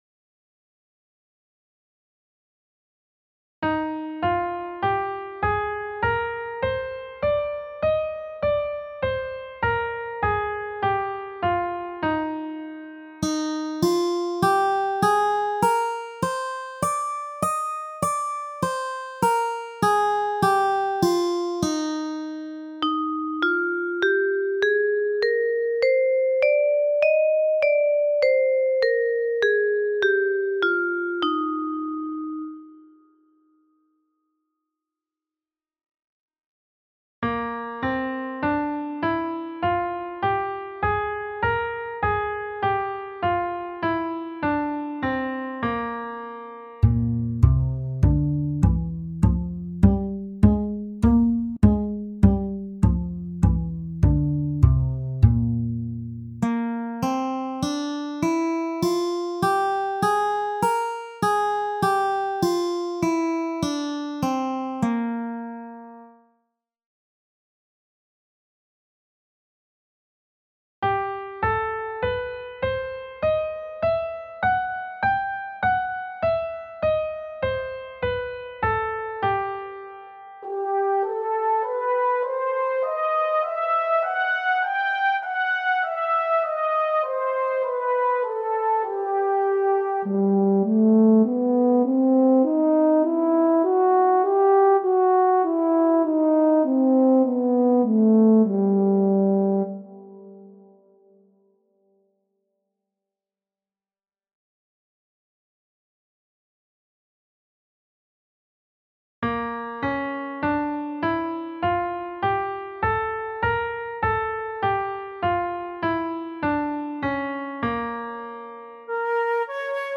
Riconoscimento e identificazione delle scale modali relative a quelle maggiori e minori